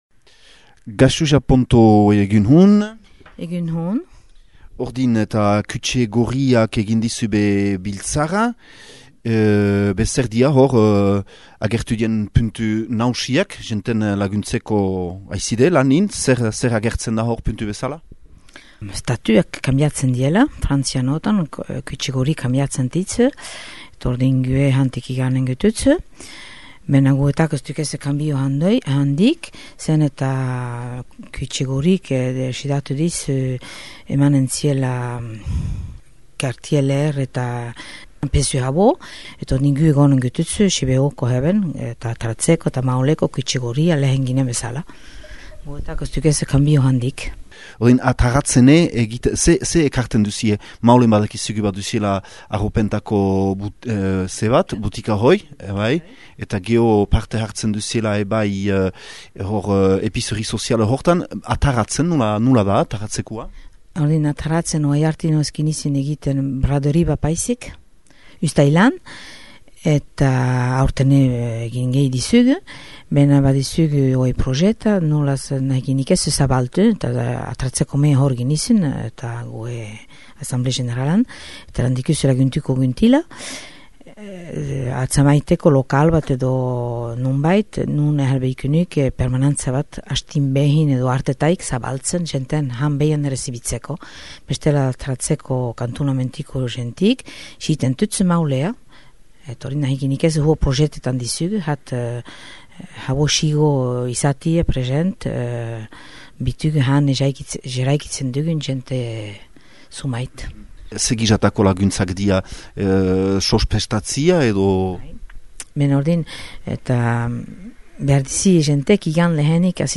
Kürütxe Gorriko bolondresa